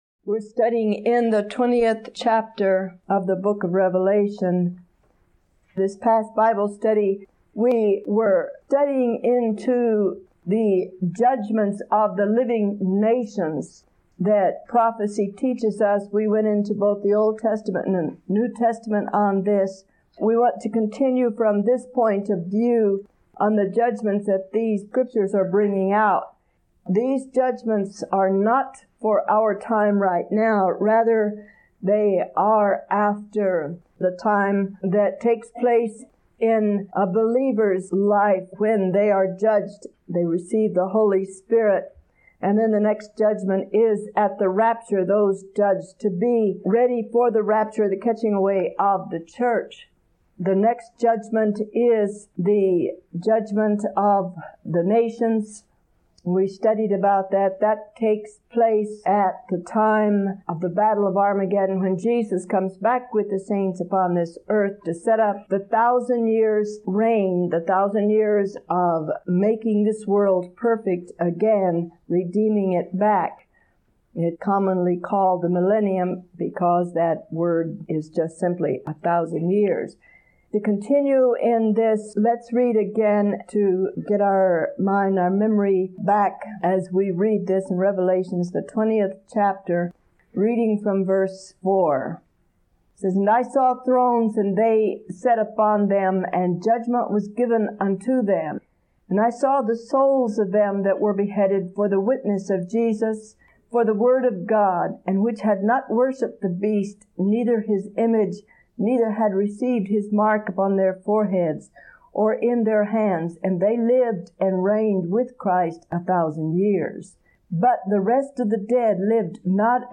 April 22, 1987 – Teaching 64 of 73